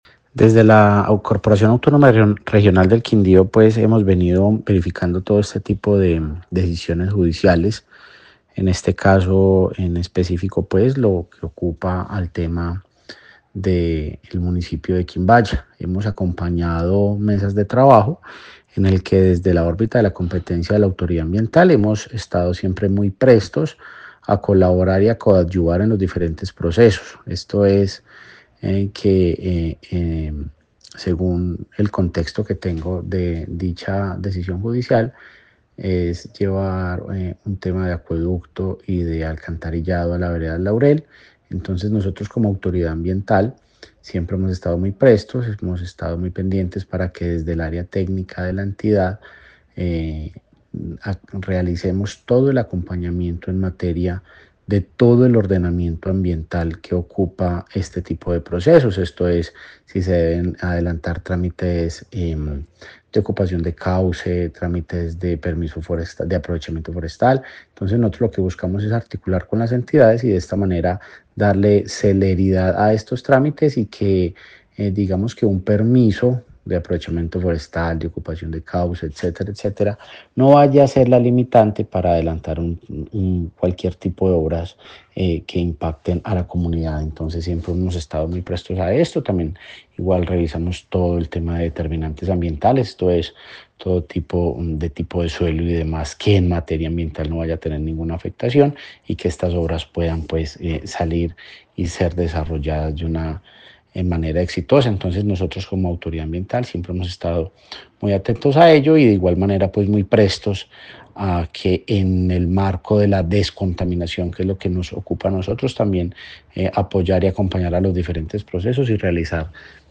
Director encargado CRQ Quindío